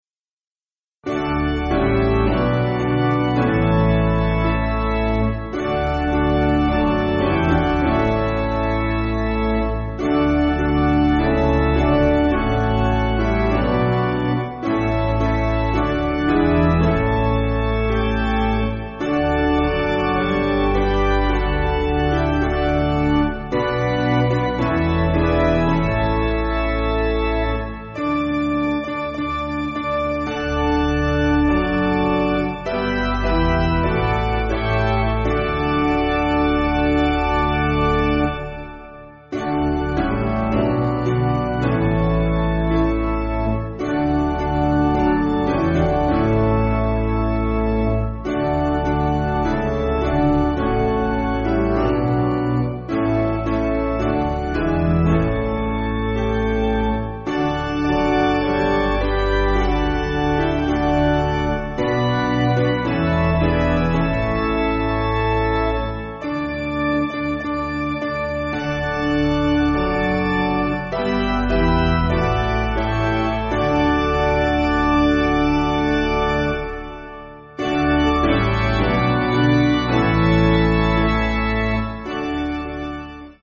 6.5.6.5.D
Basic Piano & Organ